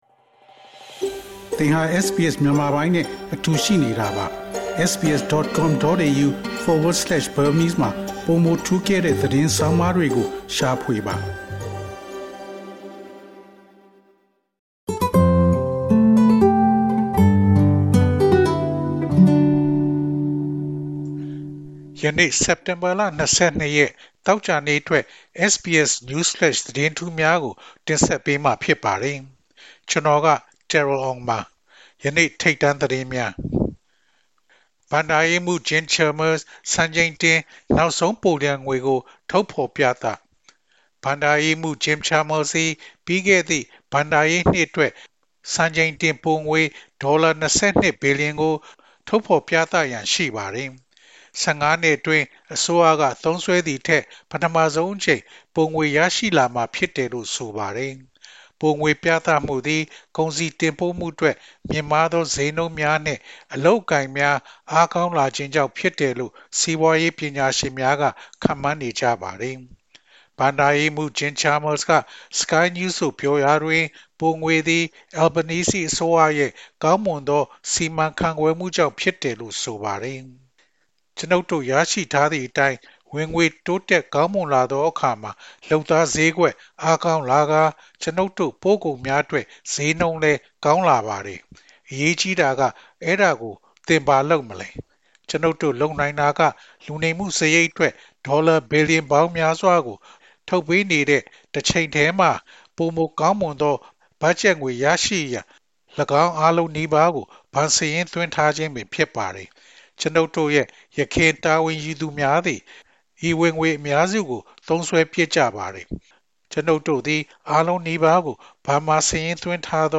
Burmese News Flash 22 Sept